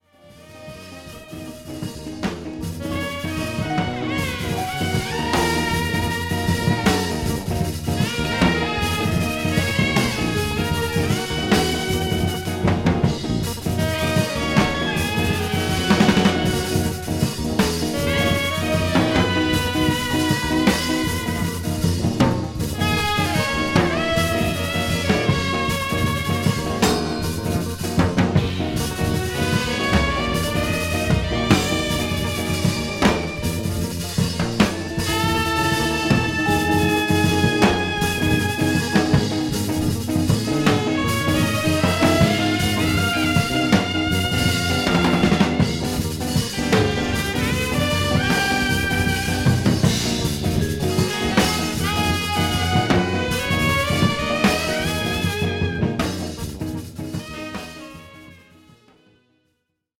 JAZZ / JAZZ FUNK / FUSION
ロック、ブルース、NW、ファンクなど